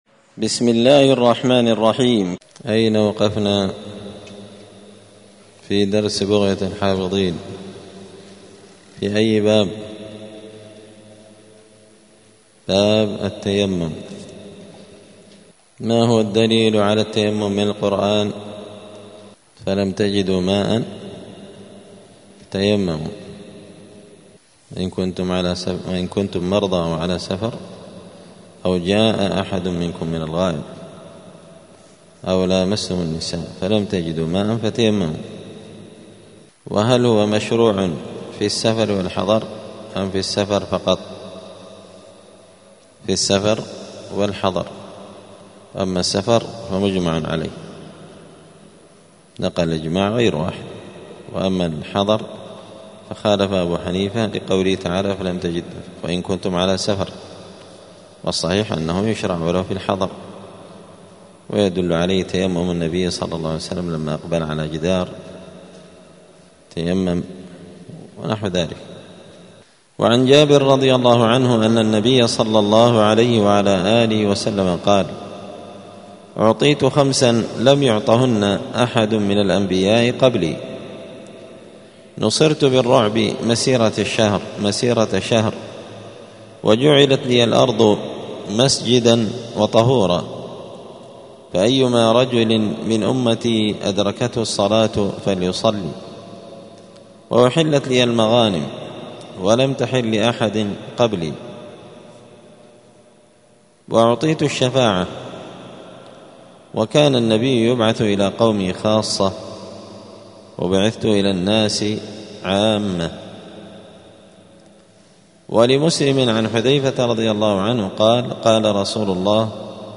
دار الحديث السلفية بمسجد الفرقان قشن المهرة اليمن
*الدرس التسعون [90] {باب صفة التيمم بيان مواضع التيمم}*